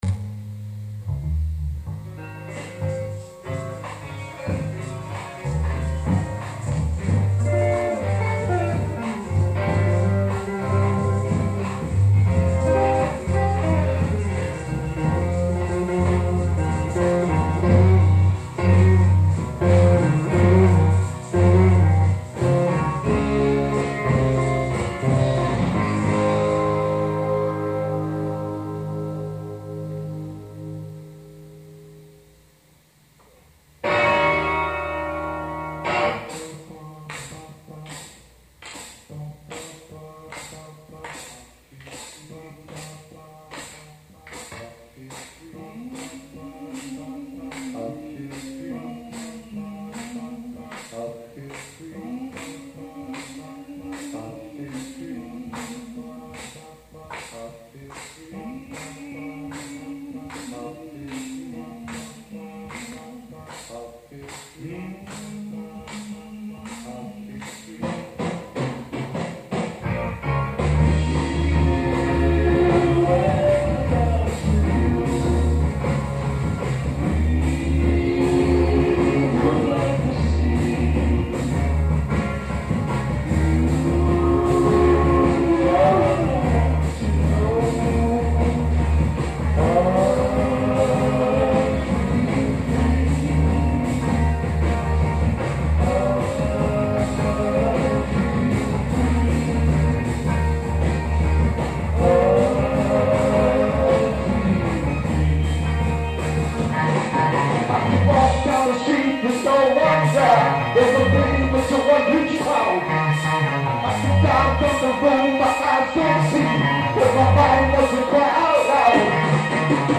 Impromptu recording
guitar
bass
drums
singing lead vocal